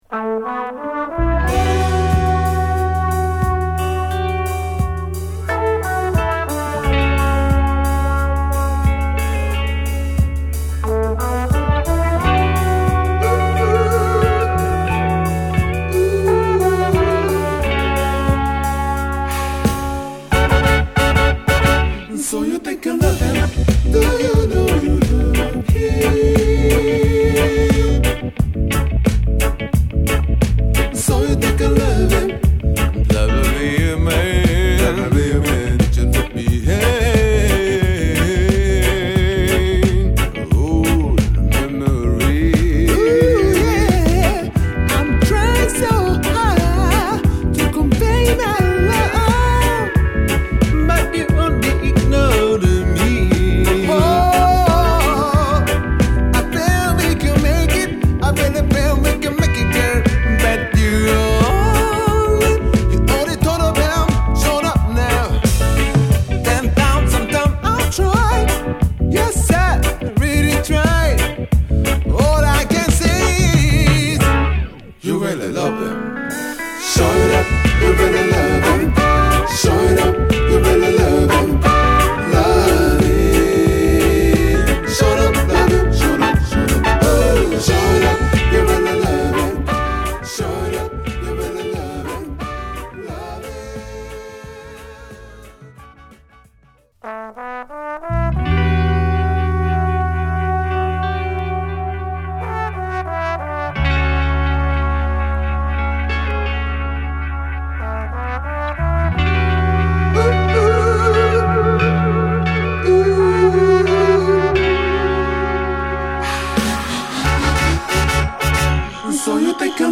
今回は70'sソウルの名曲をロックステディー調にナイスカヴァー！